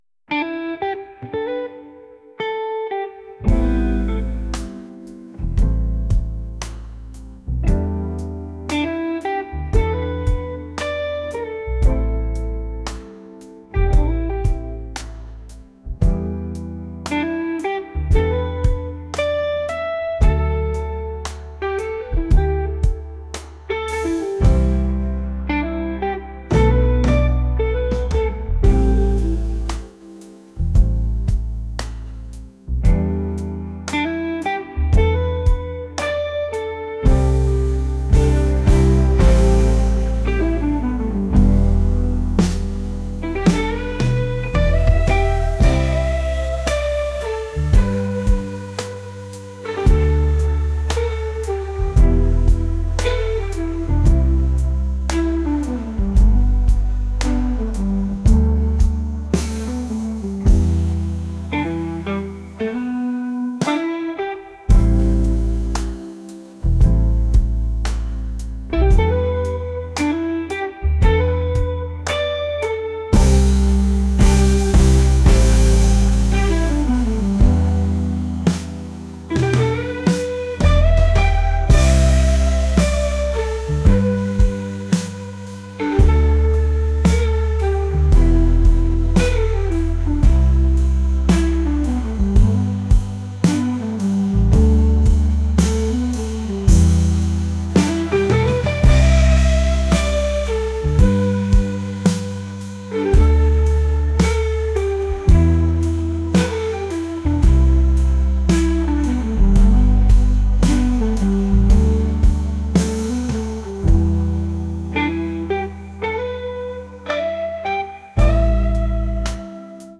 blues | soul & rnb | acoustic